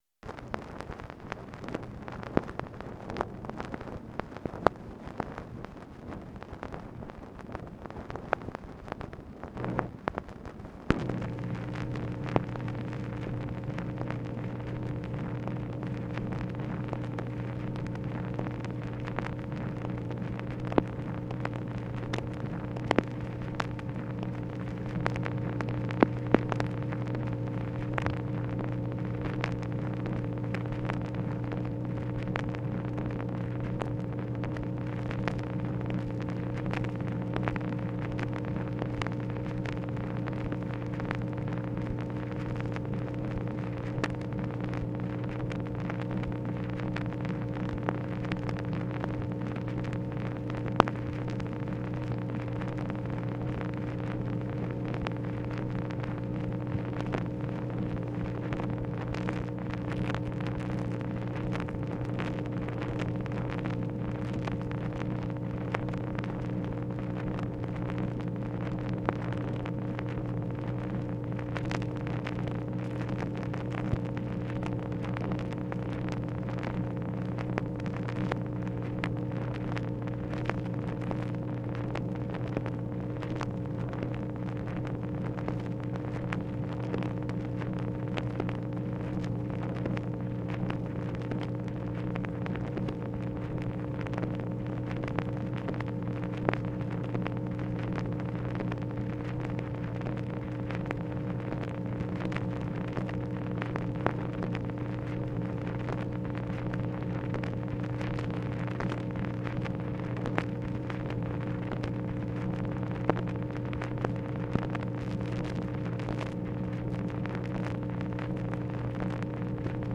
MACHINE NOISE, August 24, 1964
Secret White House Tapes | Lyndon B. Johnson Presidency